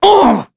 Evan-Vox_Damage_02.wav